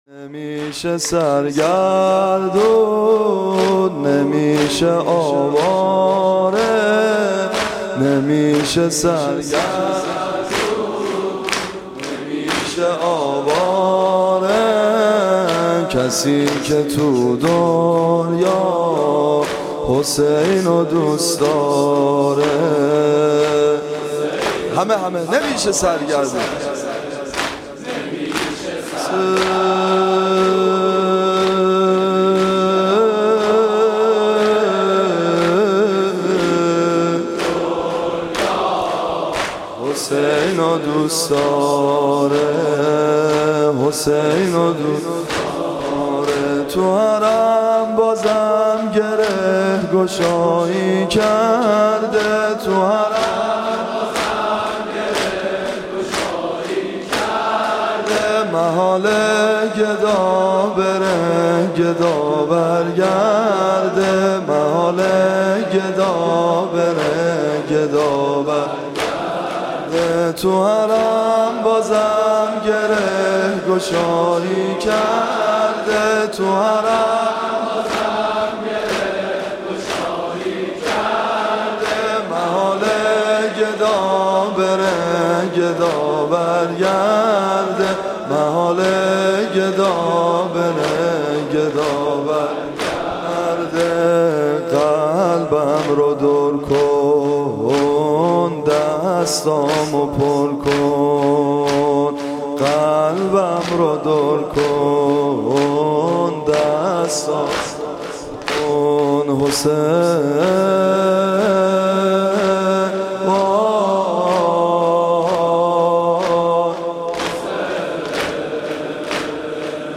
مداحی
صوت مراسم شب سوم محرم ۱۴۳۷ هیئت ابن الرضا(ع) ذیلاً می‌آید: